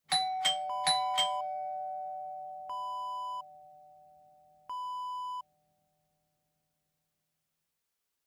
Doorbell Ding-Dong Wav Sound Effect #5
Description: Doorbell 'double' ding-dong
Properties: 48.000 kHz 24-bit Stereo
A beep sound is embedded in the audio preview file but it is not present in the high resolution downloadable wav file.
doorbell-preview-5.mp3